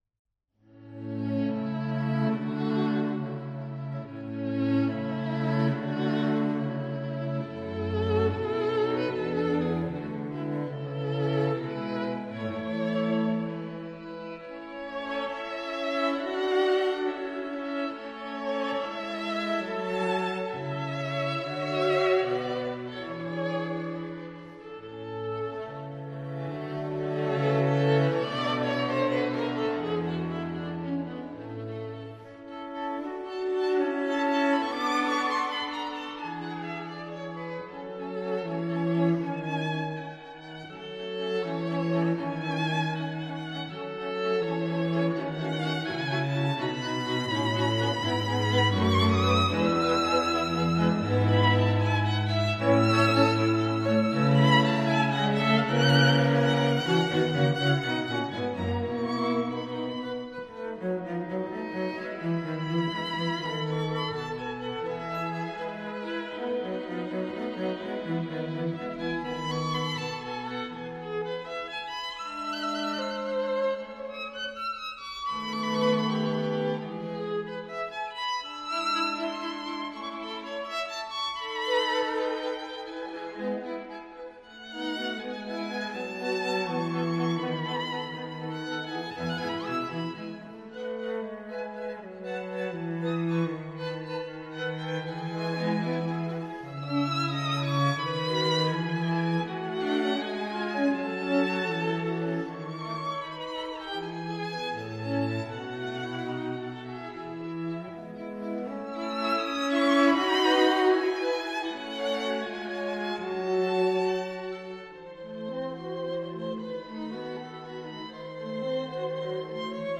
The second movement is a singing Adagio.